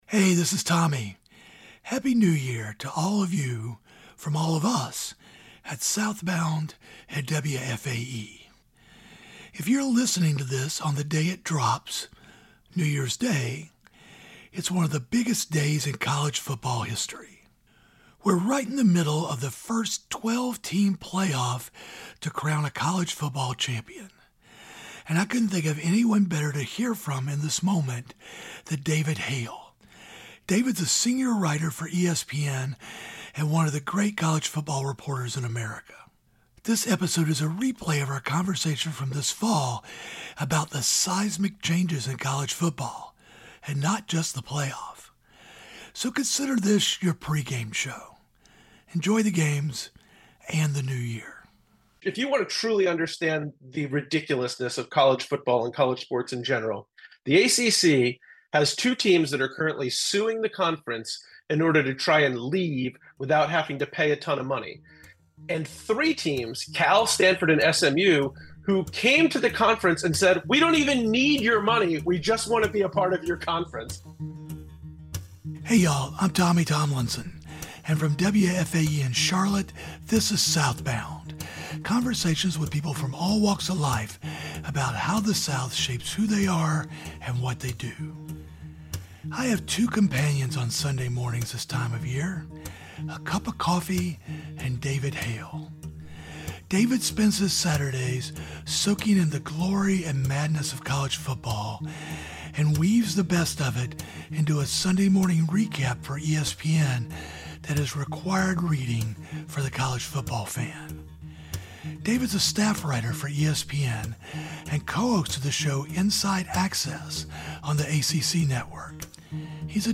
SouthBound, a new podcast series from WFAE, talks to people who were born and raised in the South.